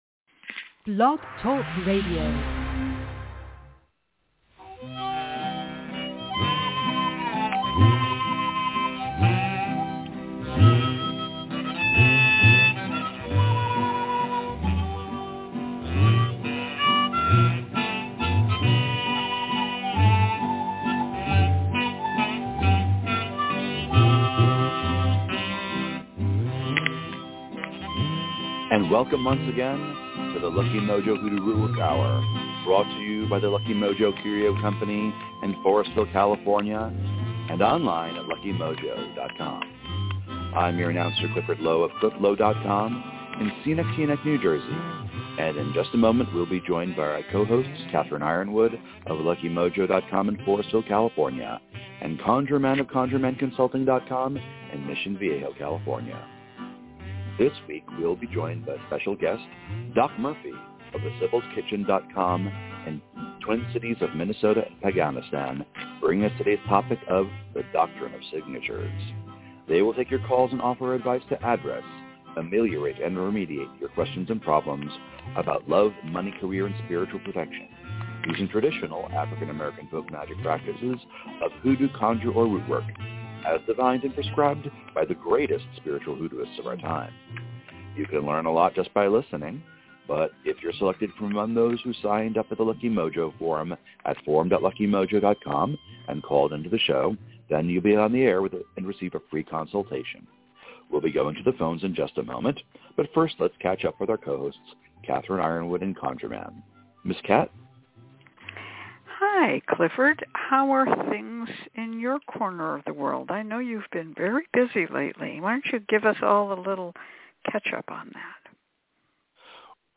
followed by free psychic readings, hoodoo spells, and conjure consultations, giving listeners an education in African-American folk magic.